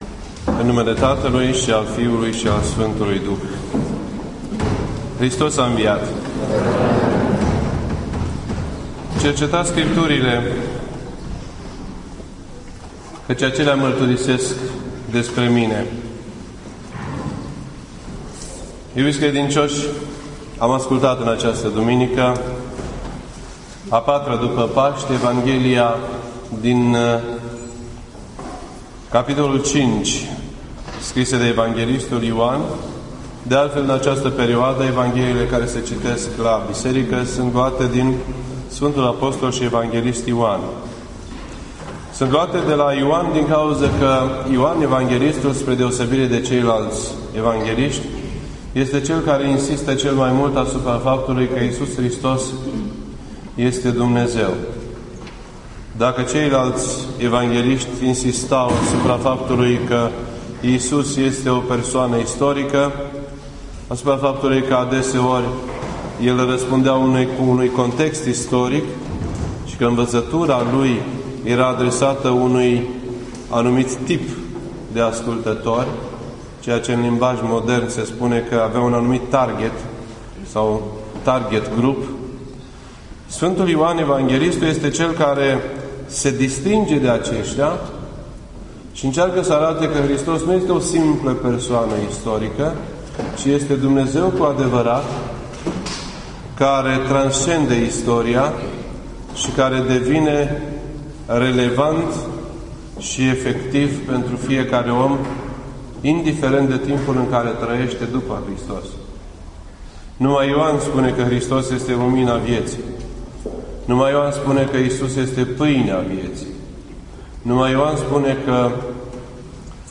Predica la Duminica Slabanogului http